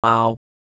Worms speechbanks
ow1.wav